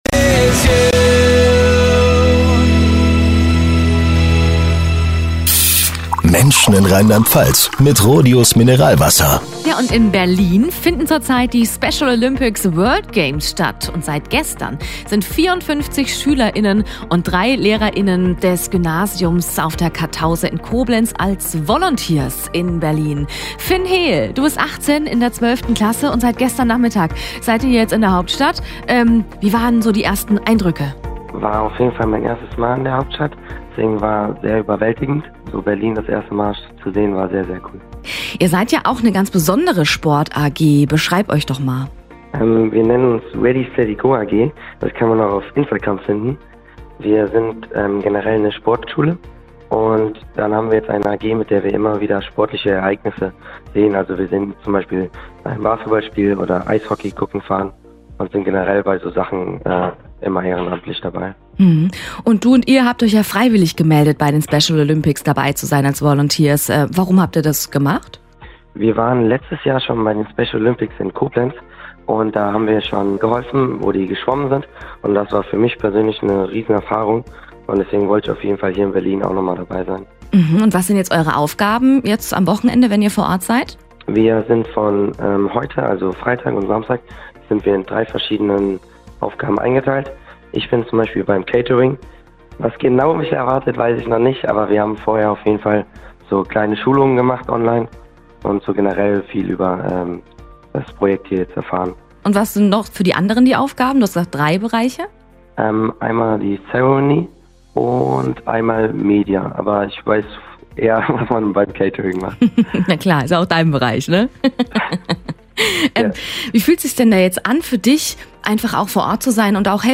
Dazu ein Radiobeitrag von RPR1.